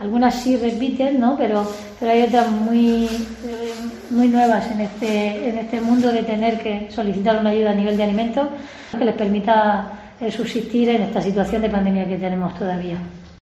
María Dolores Chumillas, edil de Servicios Sociales del Ayuntamiento de Lorca